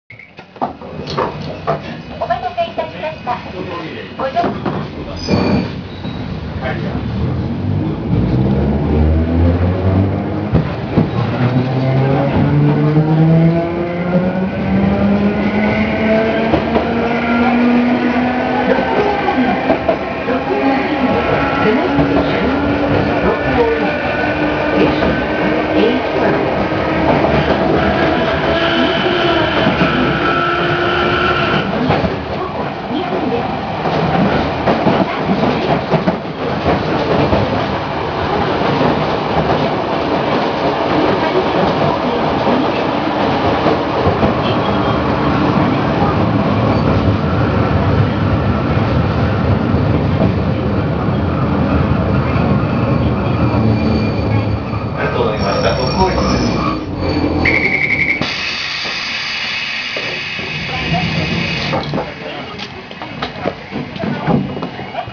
〜車両の音〜
・モボ101形走行音
【嵐山本線】嵐電嵯峨→鹿王院（1分5秒)
そのため、路面電車にありがちな音の弱いツリカケではなく本格的なツリカケの音が聞けます。一応車内自動放送（しかもちゃんと英語付き）があるのですが、この重々しい走行音に阻まれてほとんど聞こえません。
最後に聞こえるベルはドアが開く時の音です。